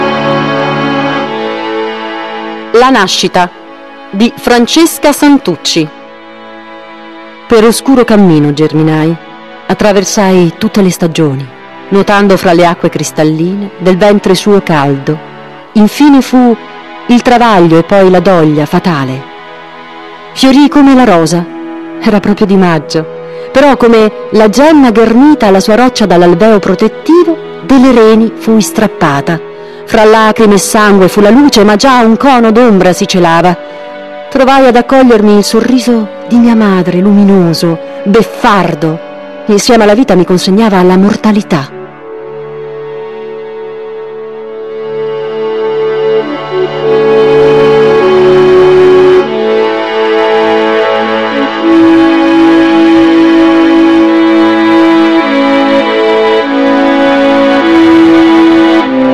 voce recitante